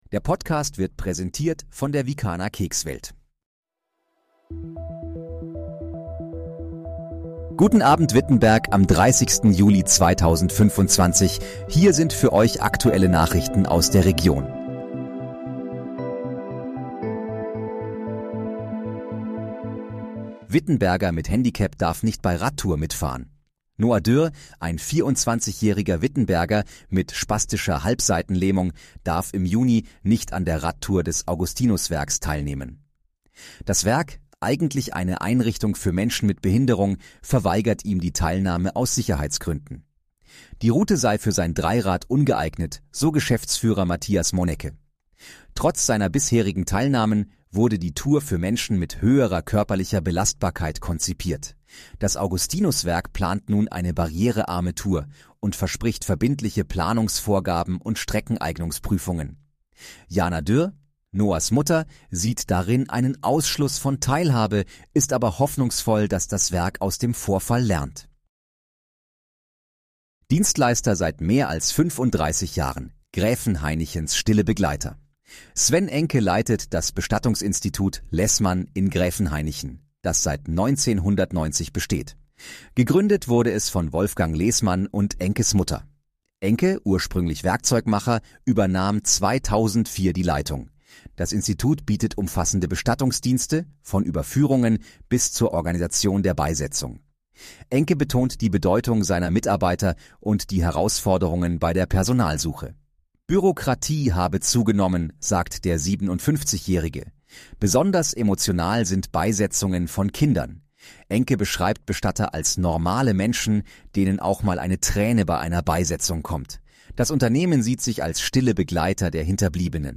Guten Abend, Wittenberg: Aktuelle Nachrichten vom 30.07.2025, erstellt mit KI-Unterstützung
Nachrichten